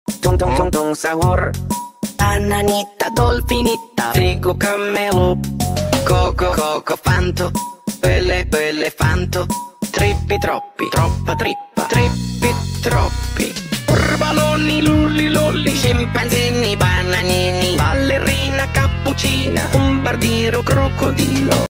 Bro singing Squid Game Brainrot sound effects free download
You Just Search Sound Effects And Download. tiktok funny sound hahaha Download Sound Effect Home